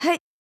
casting.wav